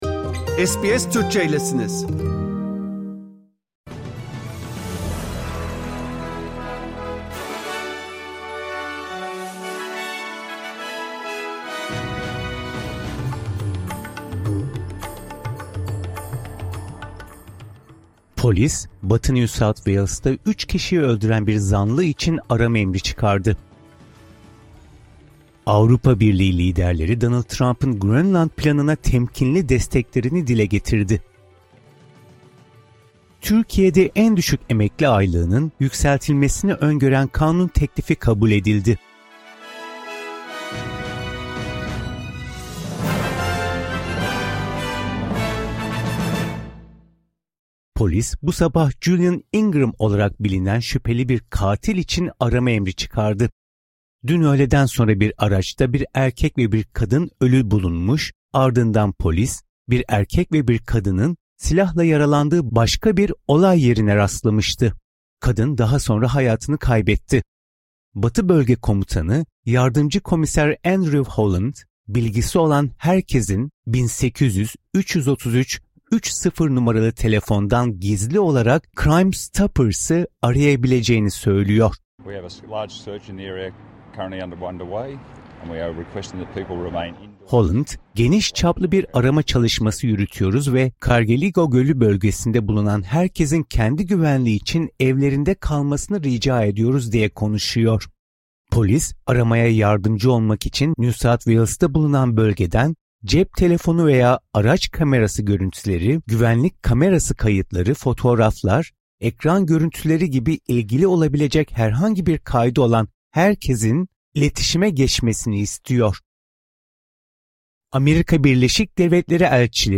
SBS Türkçe Haber Bülteni